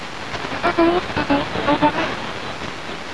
durante una registrazione con ricevitore BC 312 sulla frequenza di 6.7 Mhz in onde corte